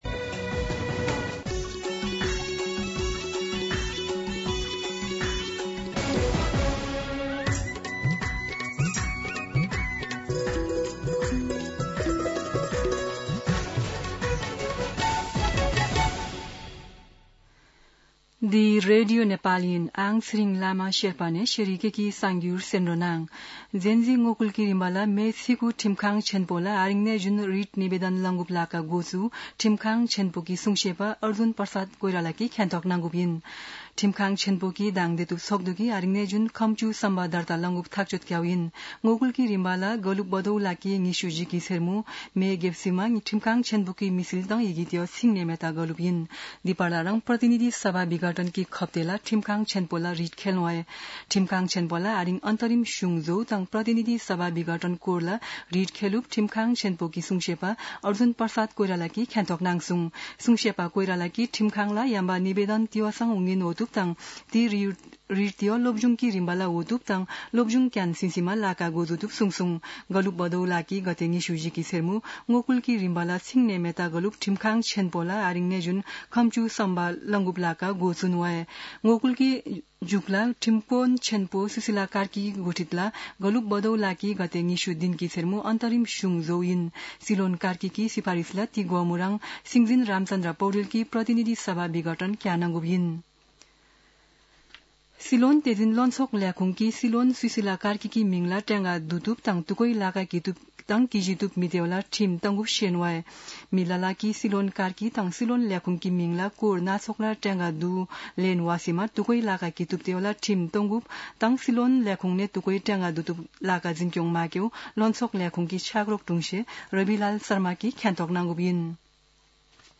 शेर्पा भाषाको समाचार : २८ असोज , २०८२